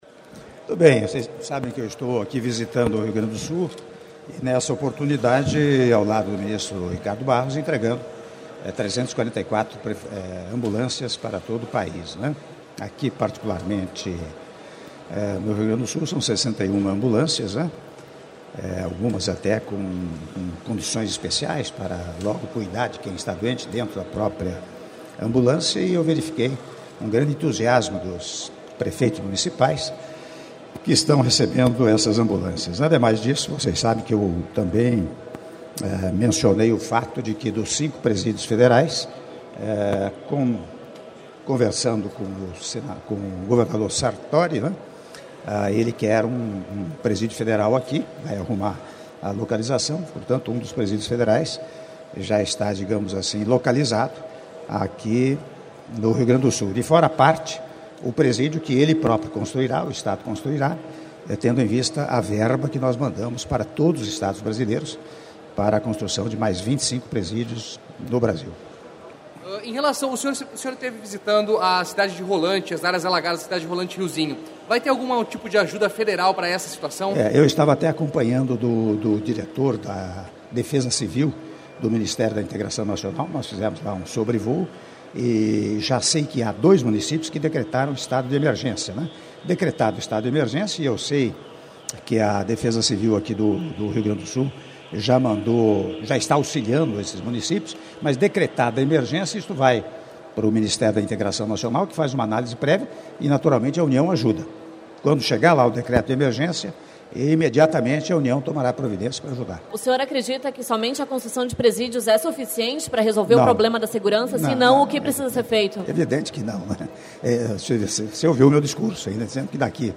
Aúdio da entrevista do presidente da República, Michel Temer, após a cerimônia de anúncio de renovação da frota de ambulâncias do SAMU: 340 veículos para o país, 61 para o Rio Grande do Sul- Esteio/RS (02min59s)